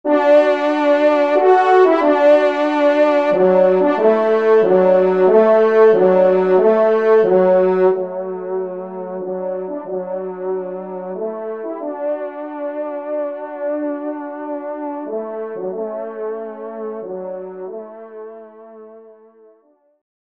Genre : Fantaisie Liturgique pour quatre trompes
Pupitre 2°Trompe